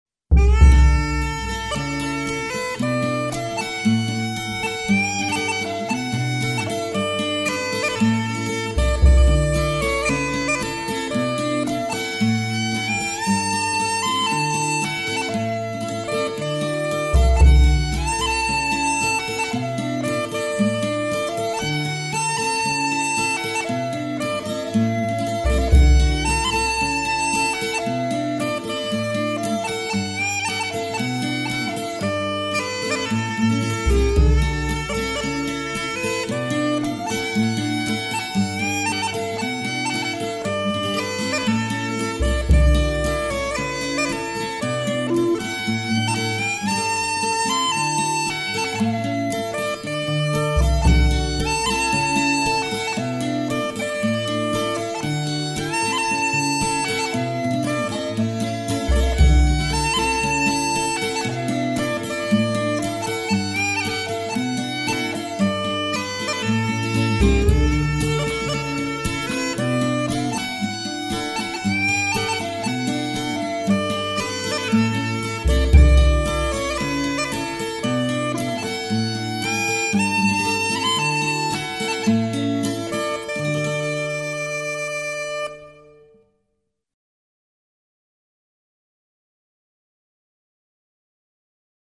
个人觉得其唱功不是很出色，好歌也不多